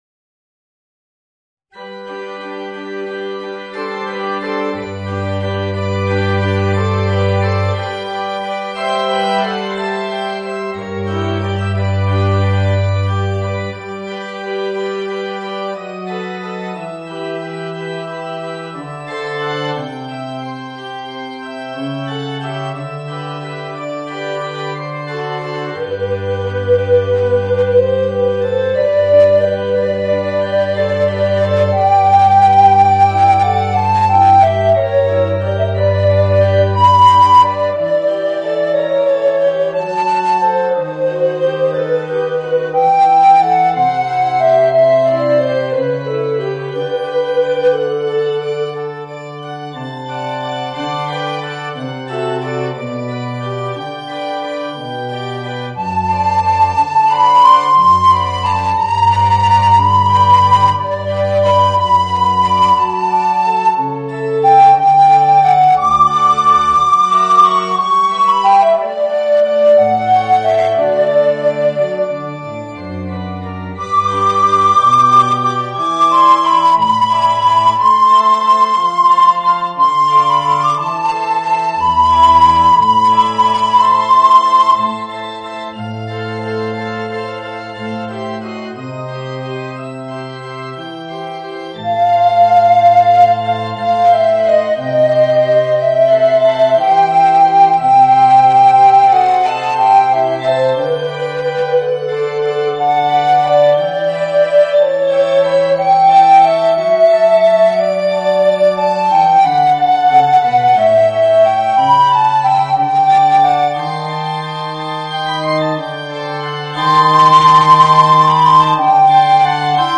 Voicing: Alto Recorder and Organ